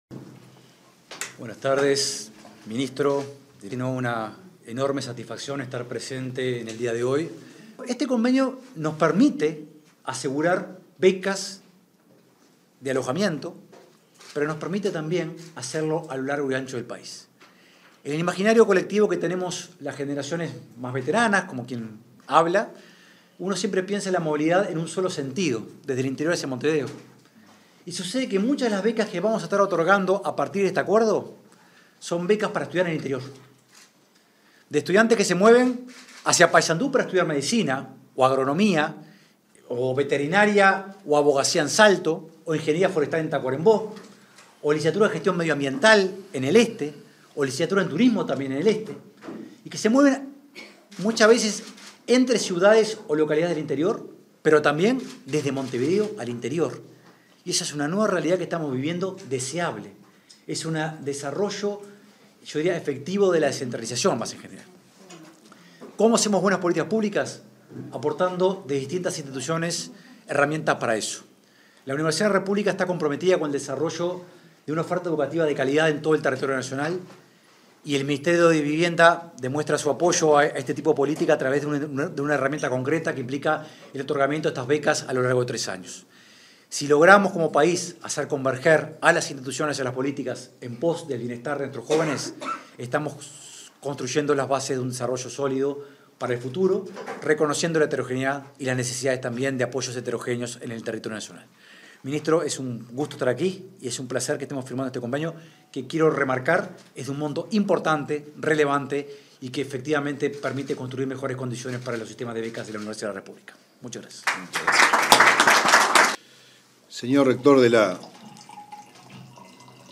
Ceremonia de firma de convenio entre MVOT y la Udelar
Ceremonia de firma de convenio entre MVOT y la Udelar 21/06/2024 Compartir Facebook X Copiar enlace WhatsApp LinkedIn En el marco de la firma de un convenio para facilitar la garantía de alquiler a estudiantes universitarios, este 21 de junio, se expresaron el ministro de Vivienda y Ordenamiento (MVOT), Raúl Lozano, y el rector de la Universidad de la República (Udelar), Rodrigo Arim.